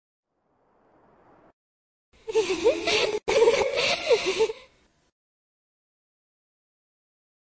Звук смеха девушки-призрака после заклинания